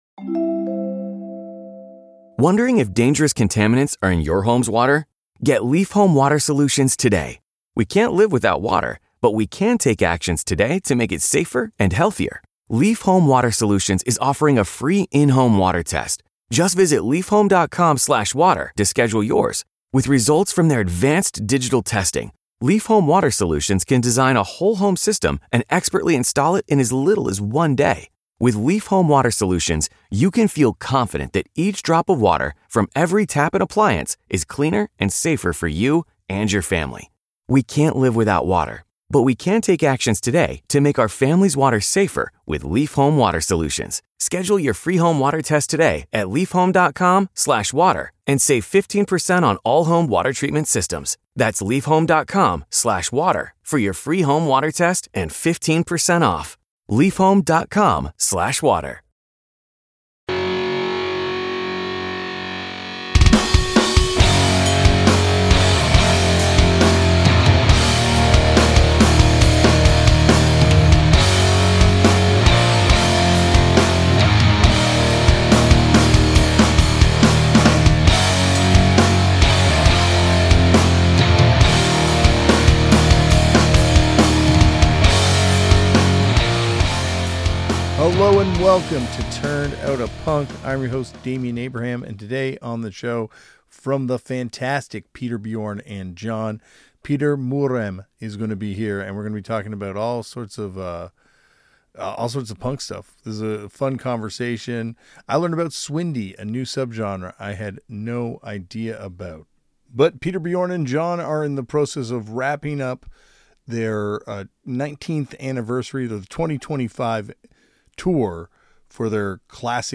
Be a guest on this podcast Language: en Genres: Music , Music History , Music Interviews Contact email: Get it Feed URL: Get it iTunes ID: Get it Get all podcast data Listen Now... Paul Langlois from The Tragically Hip is here
Listen in as Damian sits down with the Tragically Hip guitarist to talk Canadian humbleness and all things punk!